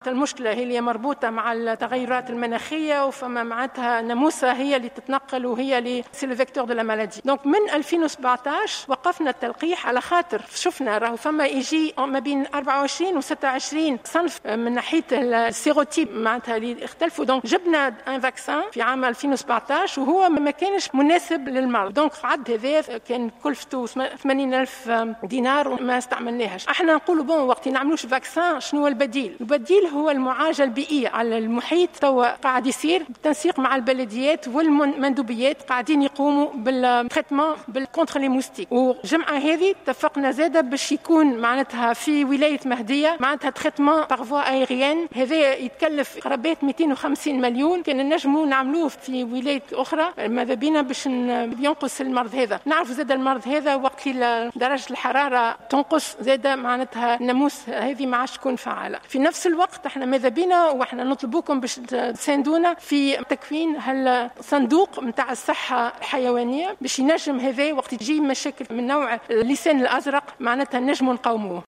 وأفادت الوزيرة في جلسة إستماع لها اليوم بالبرلمان، بأن الدولة إستوردت تلقيحا كلفته 80 ألف دينار، ولم يتم إستعماله لكونه لم يكن مناسبا للمرض ، فتم إيقافه سنة 2017 ، مؤكدة أن الوزارة شرعت في حملات مداواة ضد البعوض ، معبرة عن أملها في أن يتراجع مرض اللسان الأزرق مع تراجع درجات الحرارة.